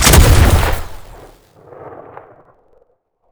rifle1.wav